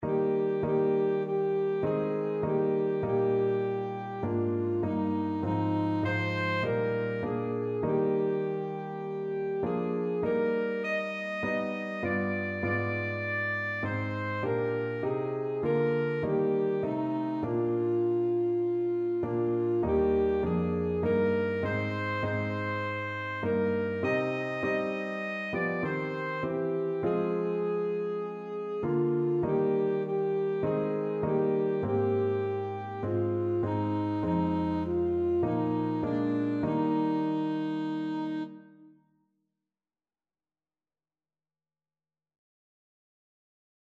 Alto Saxophone
4/4 (View more 4/4 Music)
Classical (View more Classical Saxophone Music)